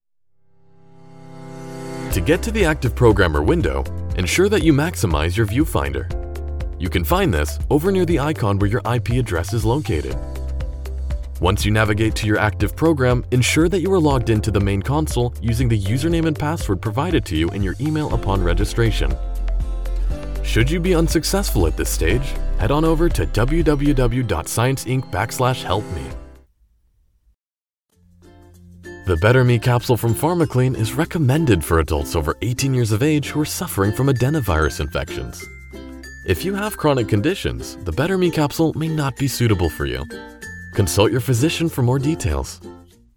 Resonant, calming, powerful, and authentic.
English - USA and Canada
Young Adult
Middle Aged